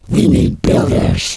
alien_needbuilders2.wav